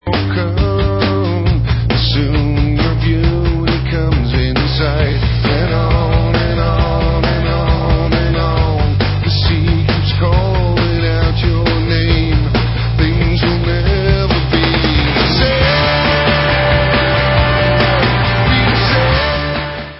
Allstar dutch rockband